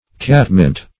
Catnip \Cat"nip`\, Catmint \Cat"mint`\, n. (Bot.)